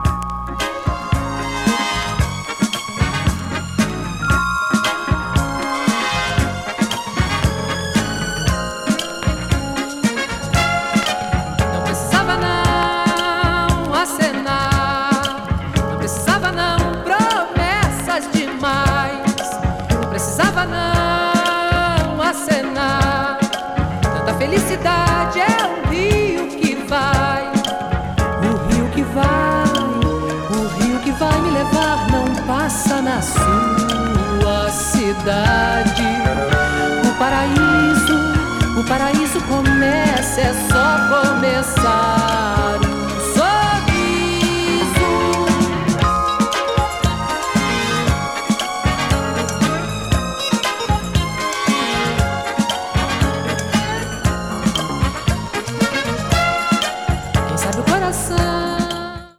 80s FUNKY LATIN / BRASIL MELLOW 詳細を表示する